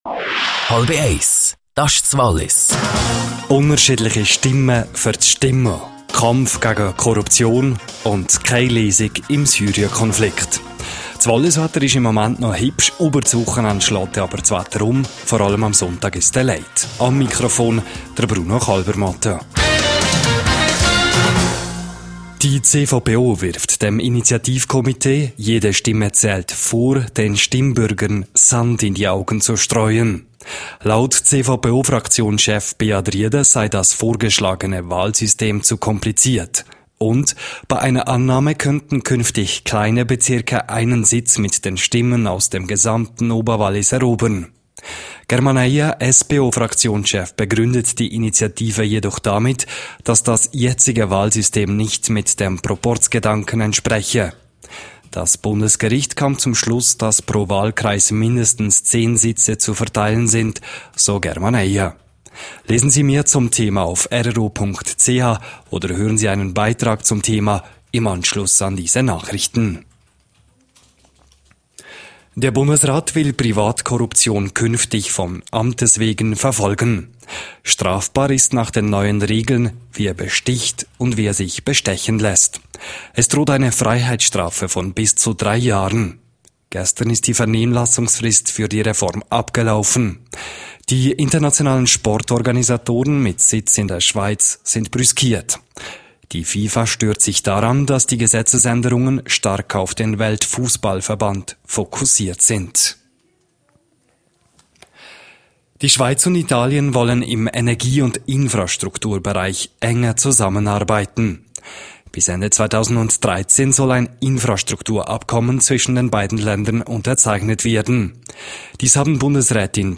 12:30 Uhr Nachrichten (5.44MB)